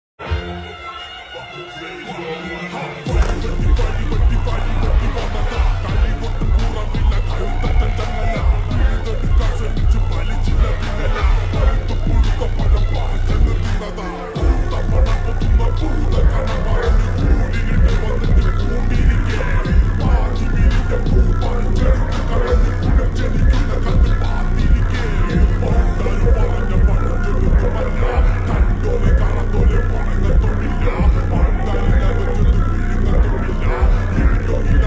Malayalam Songs